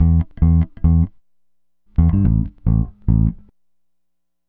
Retro Funkish Bass 01a.wav